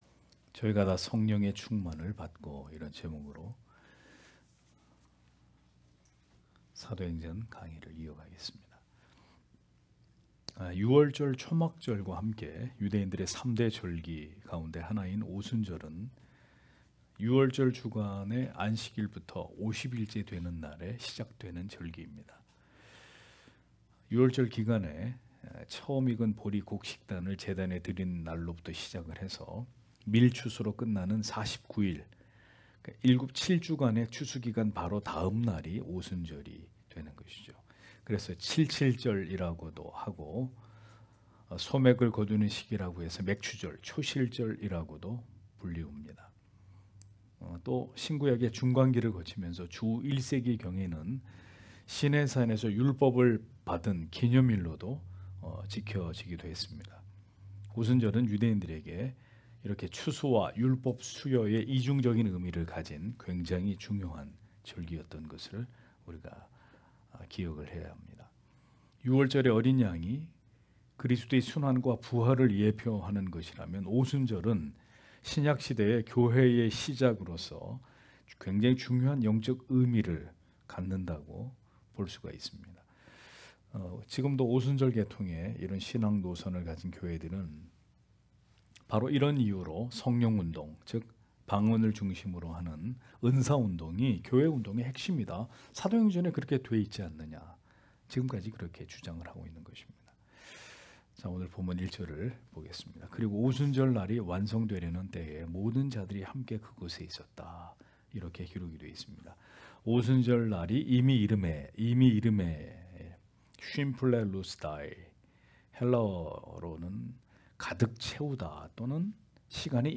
금요기도회 - [사도행전 강해 09] 저희가 다 성령의 충만을 받고 (행 2장 1- 4절)